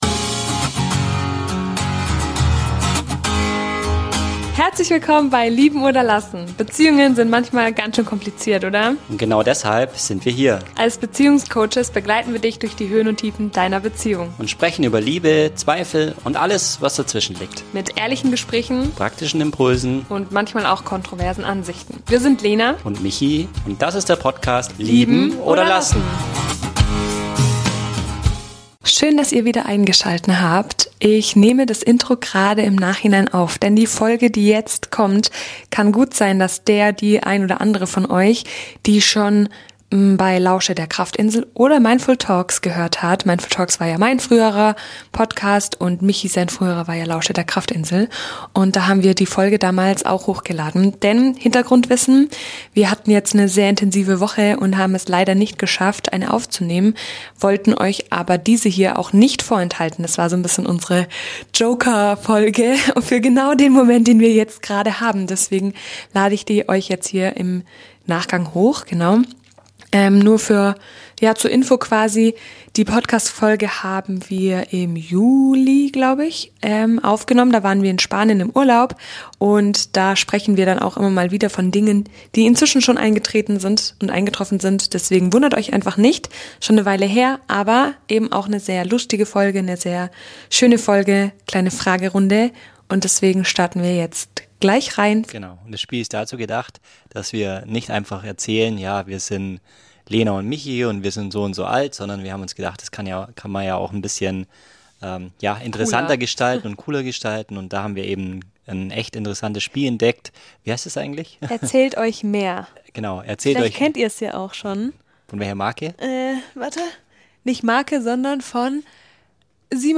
Eine Folge, die wir im Juli Urlaub aufgenommen hatte und ursprünglich dafür da war, dass ihr uns besser kennenlernt - und die heute unsere ‘Joker-Folge’ ist, weil das Leben gerade etwas hektisch ist.